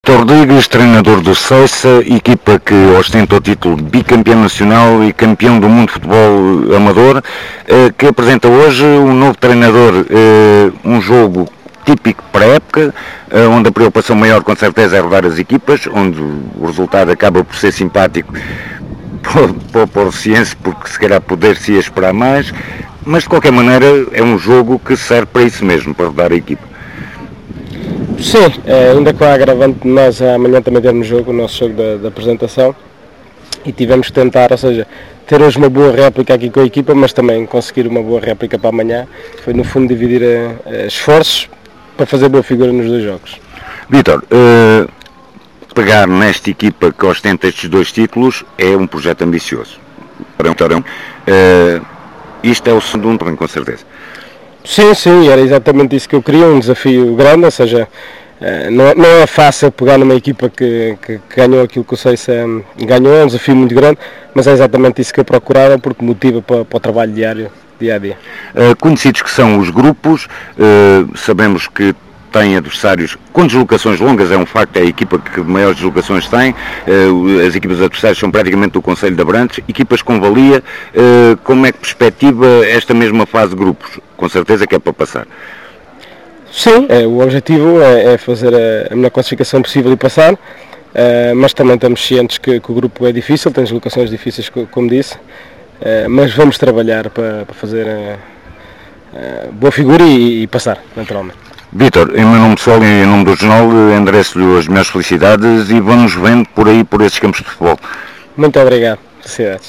No final ouvimos ambos os treinadores: